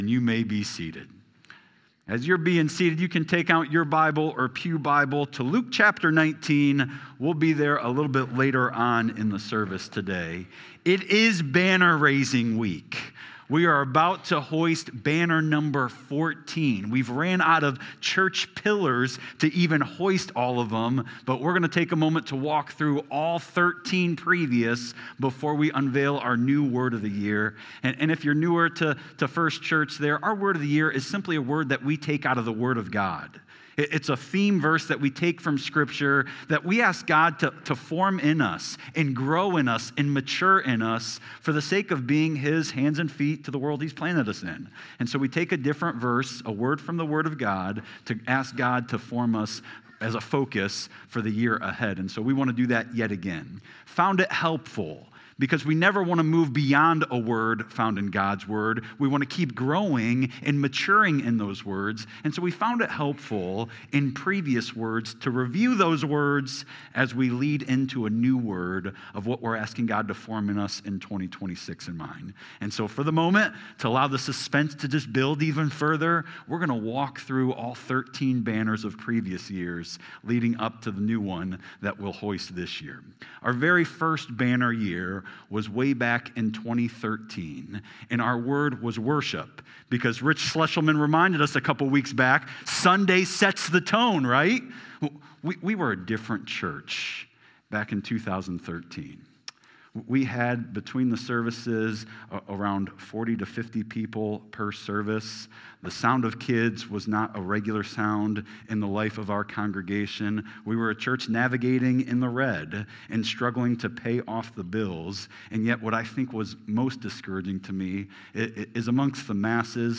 Sermons | First Church Bellevue